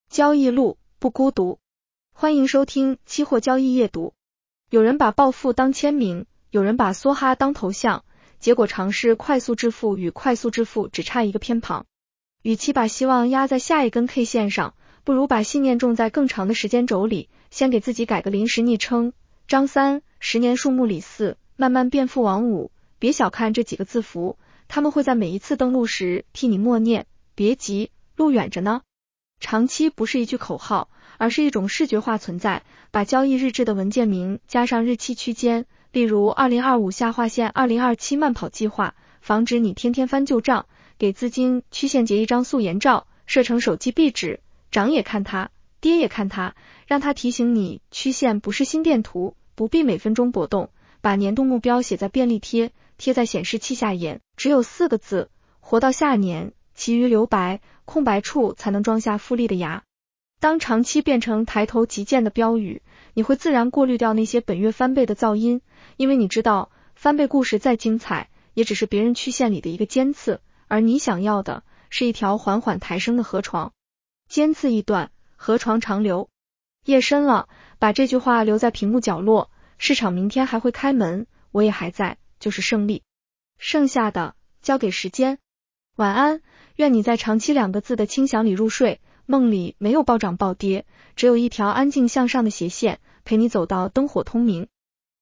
女声普通话版 下载mp3 交易路，不孤独。